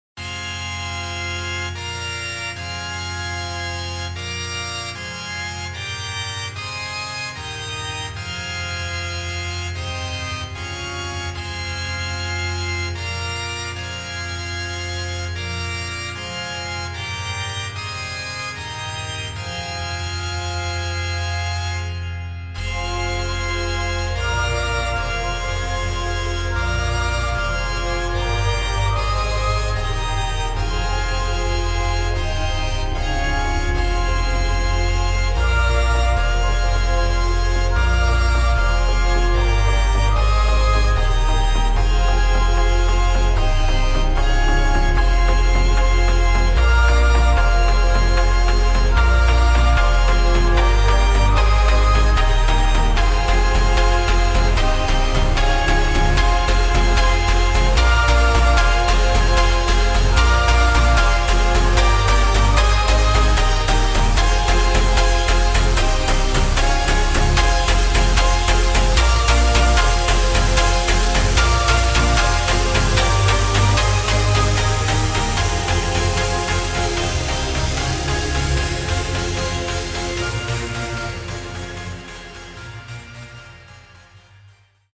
[ GHETTO / JUKE / FOOTWORK / JUNGLE / EXPERIMENTAL ]